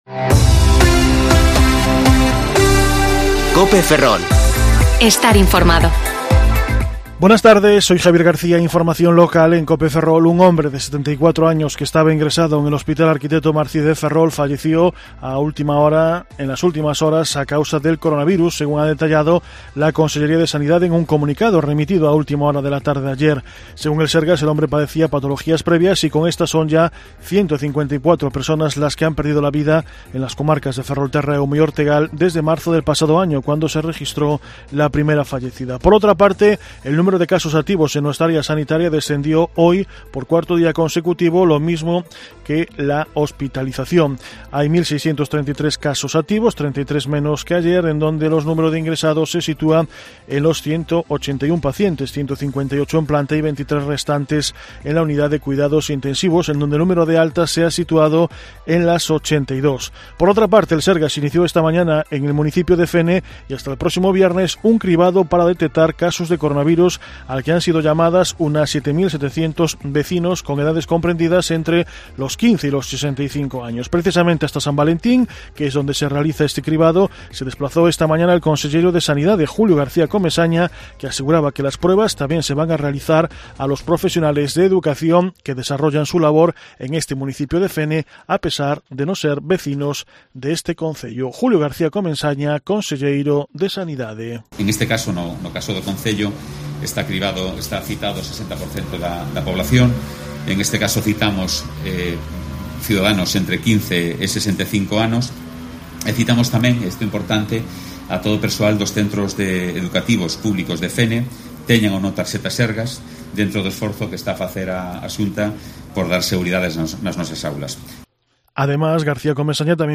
Informativo Mediodía COPE Ferrol 8/2/2021 (De 14.20 a 14.30 horas)